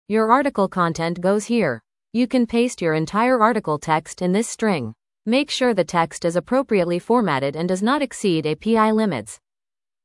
speaking_rate=0.9,  # Adjusted speech rate (can modify as needed)
volume_gain_db=5.0  # Louder volume
Google Cloud Text-to-Speech offers several voices, but Journey stands out for its natural, human-like sound. Unlike other models that often sound robotic, Journey excels in expressiveness and lifelike delivery.